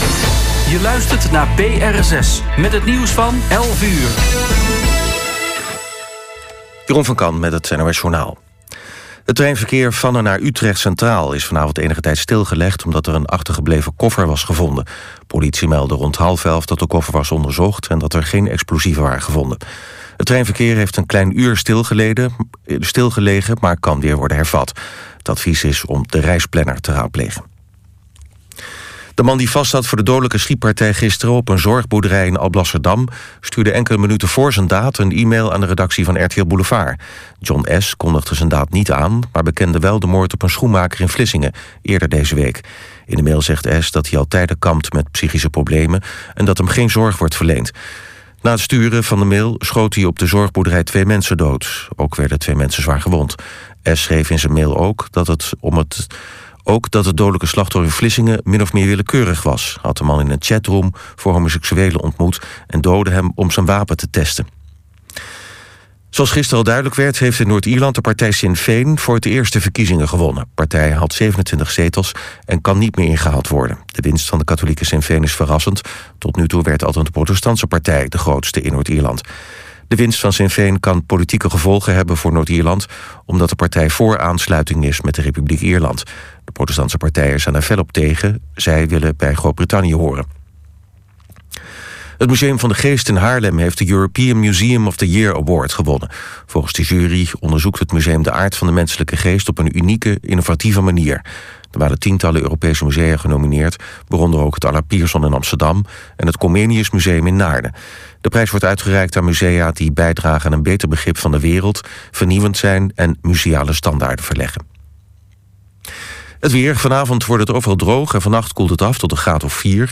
“Riverside Jazz” wordt elke zaterdagavond uitgezonden via BR6, van 23:00 tot 00:00 uur.